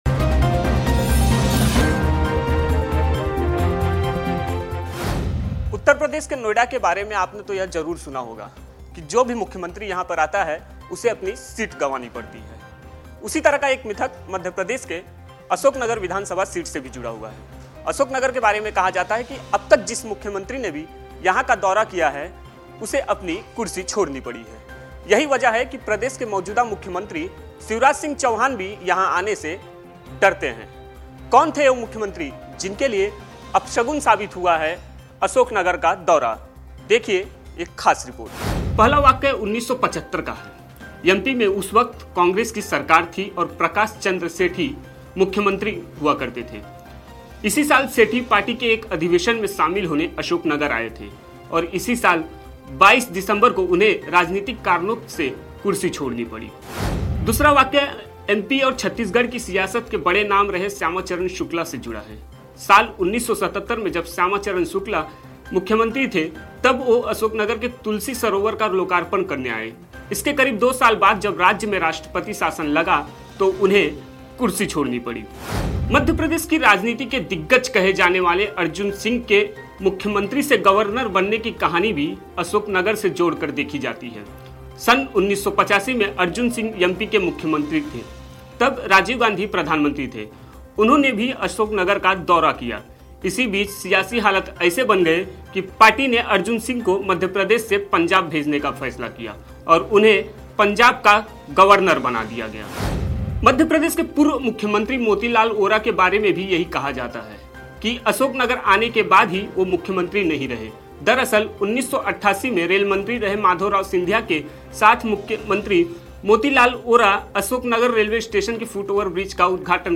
न्यूज़ रिपोर्ट - News Report Hindi / मुख्यमंत्रियों के लिए अपशगुन है मध्य प्रदेश की यह विधानसभा सीट, कुर्सी जाने का रहता है डर !